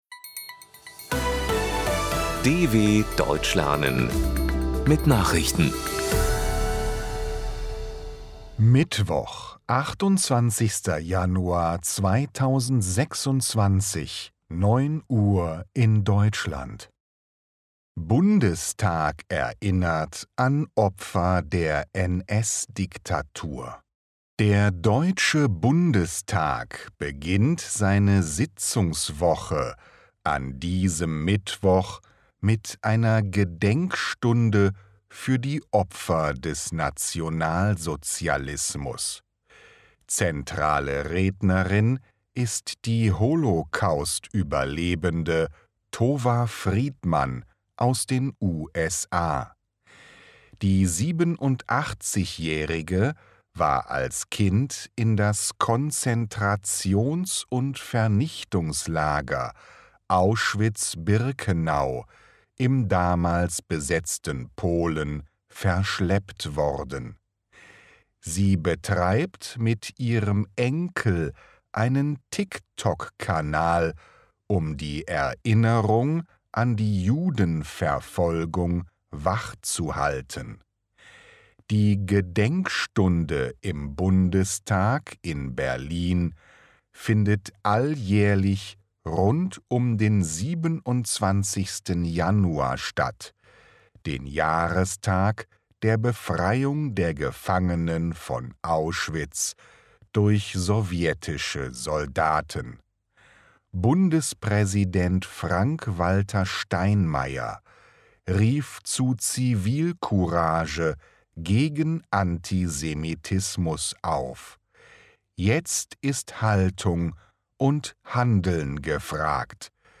28.01.2026 – Langsam Gesprochene Nachrichten
Trainiere dein Hörverstehen mit den Nachrichten der DW von Mittwoch – als Text und als verständlich gesprochene Audio-Datei.